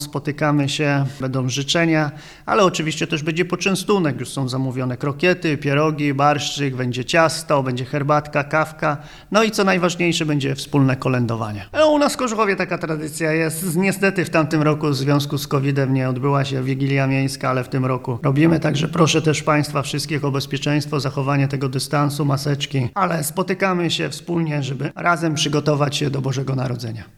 – Będzie przede wszystkim tradycyjnie – powiedział burmistrz Paweł Jagasek: